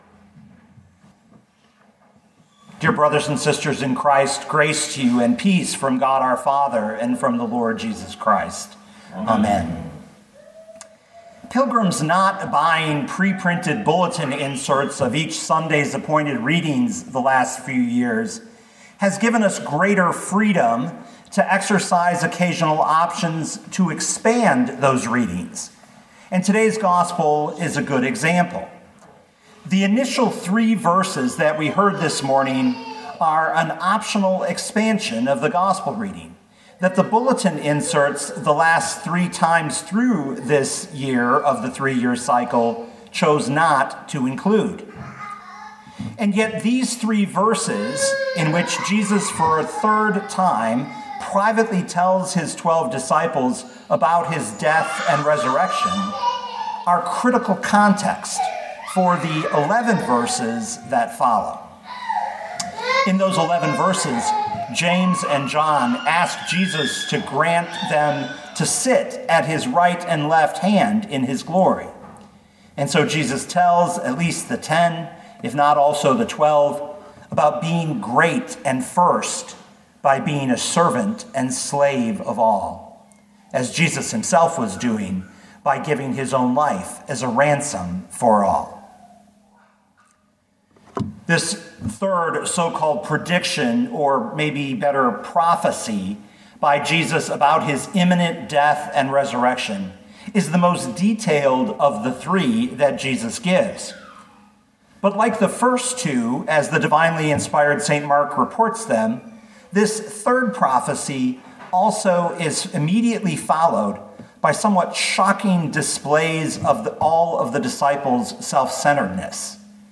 2021 Mark 10:32-45 Listen to the sermon with the player below, or, download the audio.